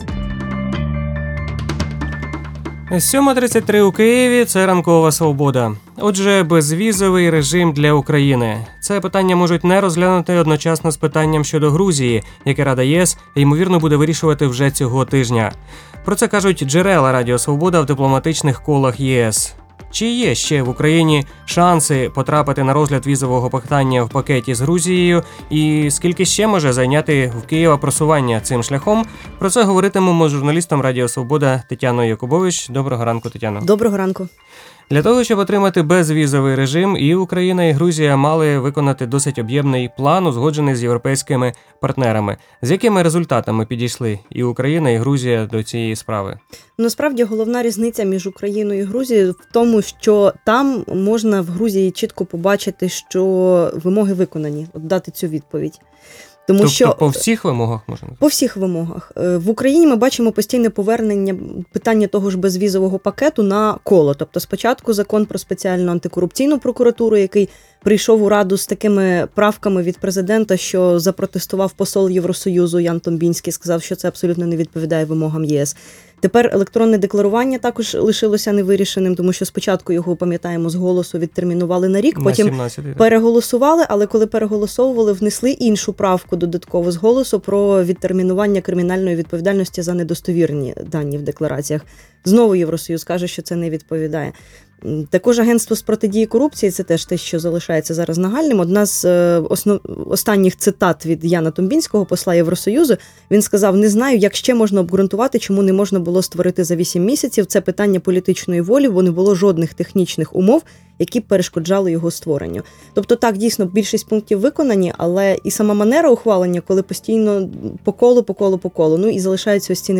Про перспективи візової лібералізації йшлося в ефірі «Ранкової Свободи».